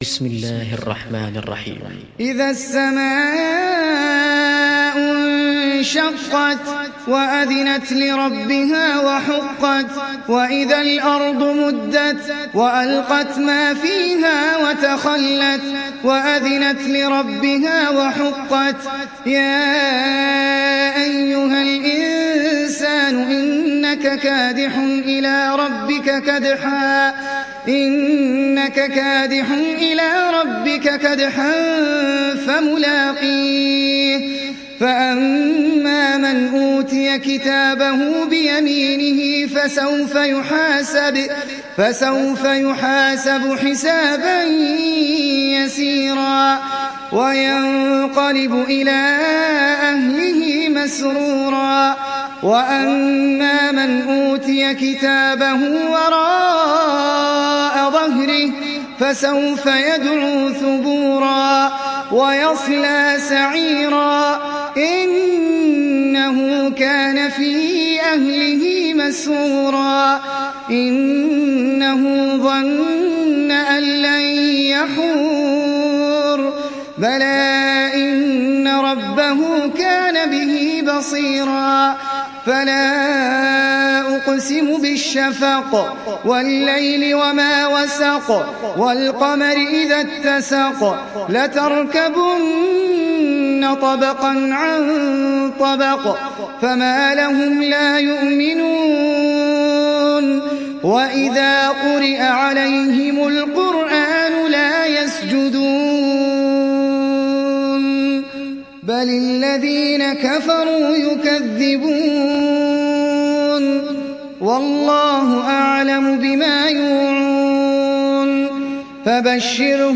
Surat Al Inshiqaq Download mp3 Ahmed Al Ajmi Riwayat Hafs dari Asim, Download Quran dan mendengarkan mp3 tautan langsung penuh